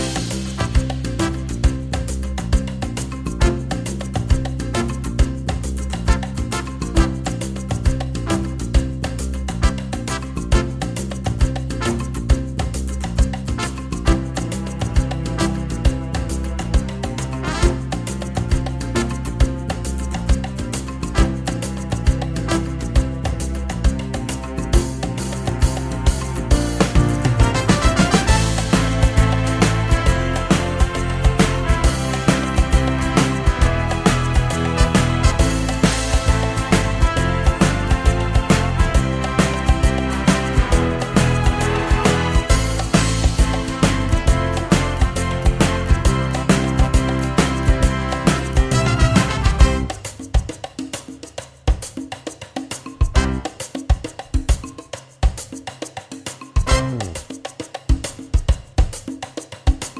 no Backing Vocals Soundtracks 2:44 Buy £1.50